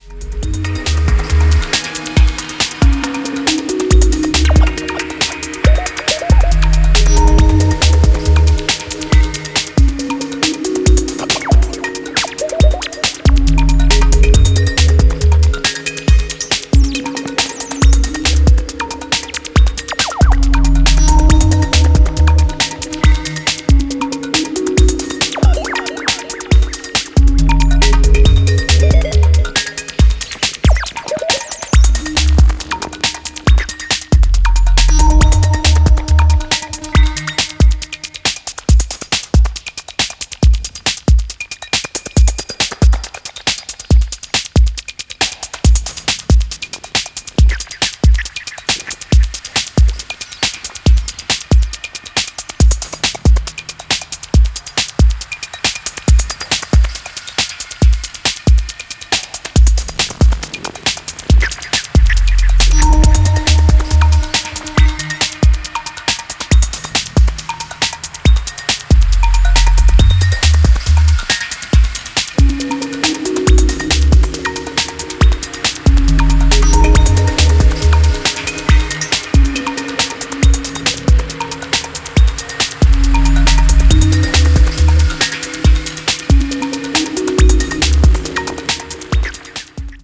a sophisticated, emotionally-charged strain of electro
Electro